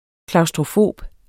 Udtale [ klɑwsdʁoˈfoˀb ]